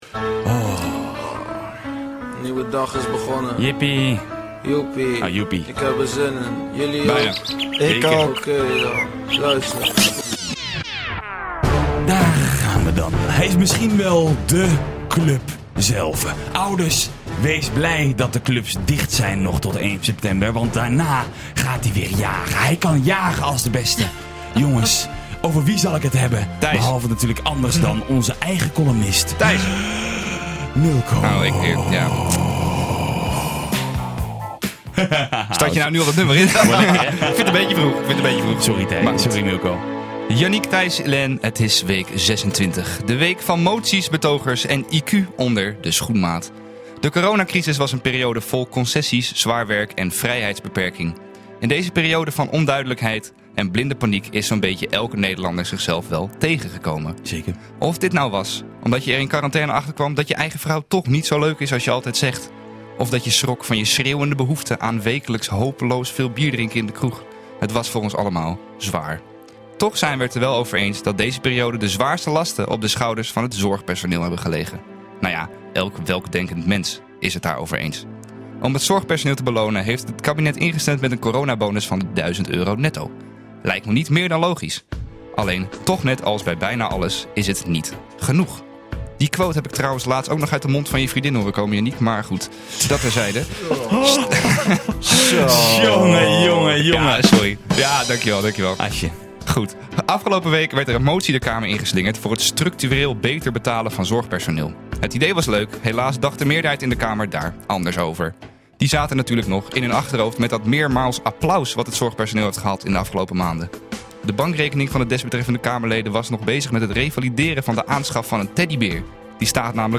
Hij is scherp, kritisch maar bovenal buitengewoon sarcastisch. Deze week in de spotlight: Moties, betogers en IQ onder de schoenmaat.